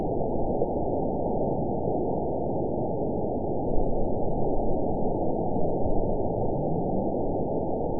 event 911250 date 02/19/22 time 04:41:12 GMT (3 years, 3 months ago) score 9.12 location TSS-AB01 detected by nrw target species NRW annotations +NRW Spectrogram: Frequency (kHz) vs. Time (s) audio not available .wav